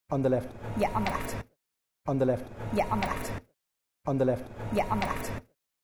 Young female Dublin speaker saying ‘on the left’ (echoing the phrase by the author who says it without lowering)
On_the_Left_(young_female_Dublin).mp3